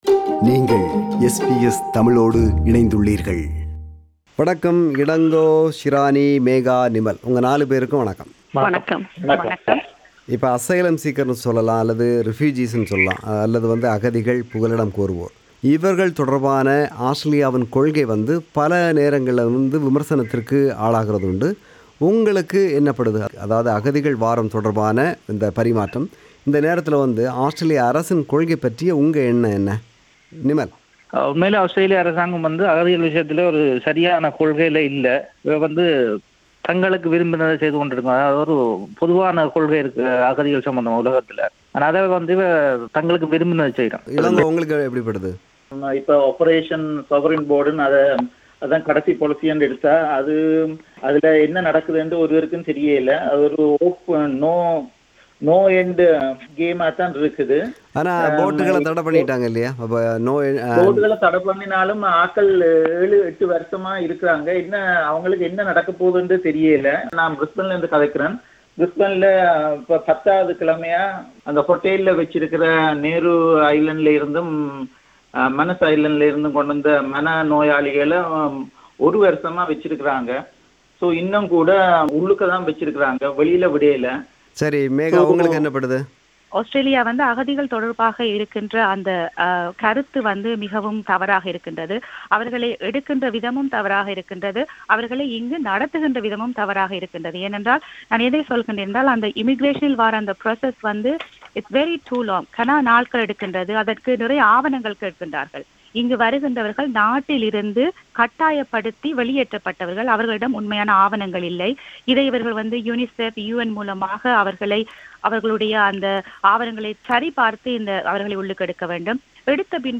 Policy on refugees and refugees’ plights: A panel discussion